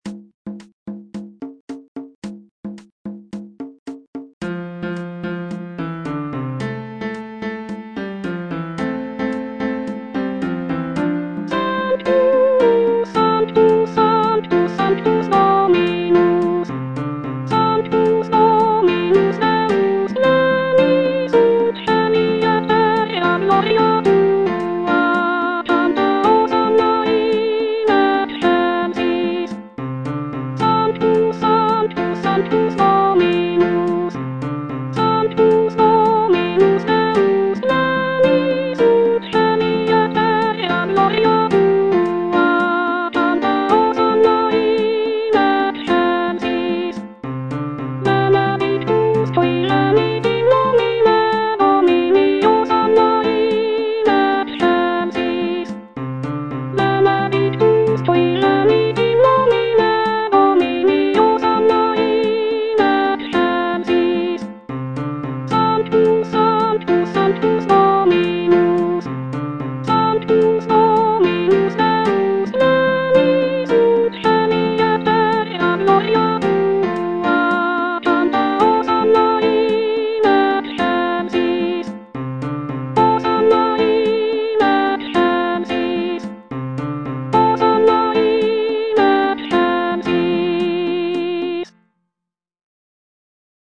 Soprano (Voice with metronome) Ads stop